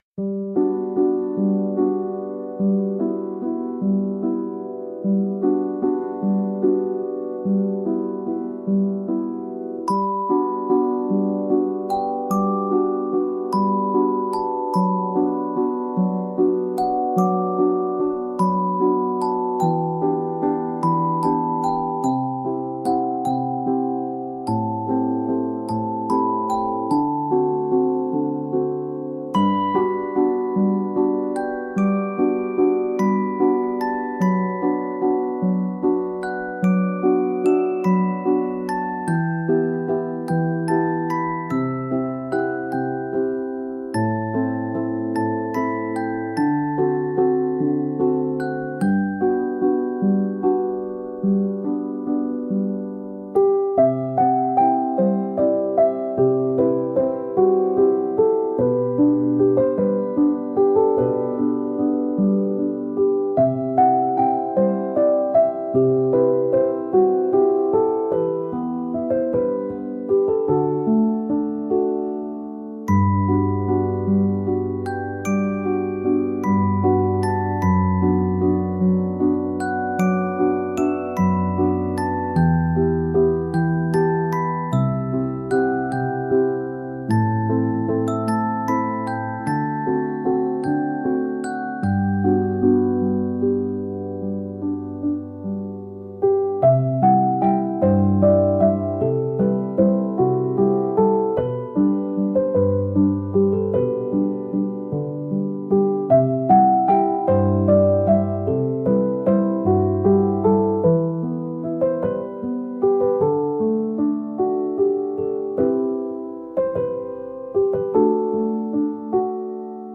「ほのぼの」